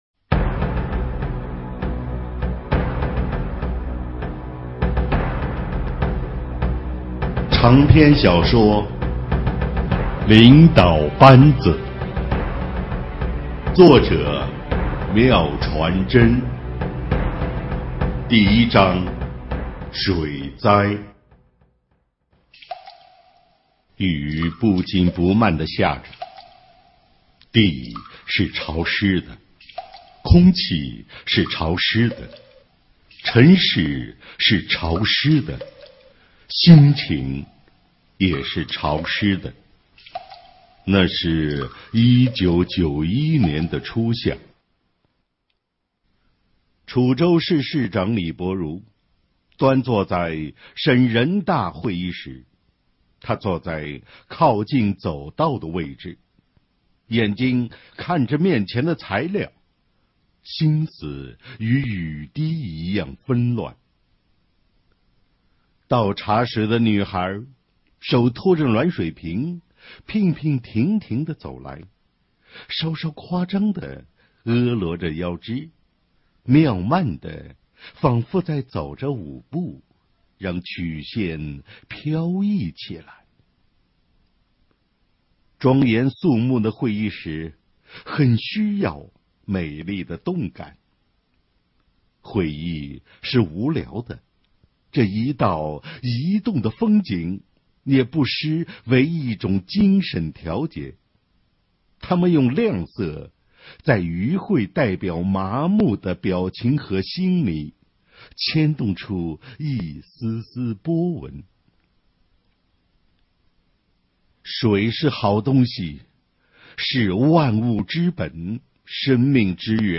【有声文学】《权力：领导班子1》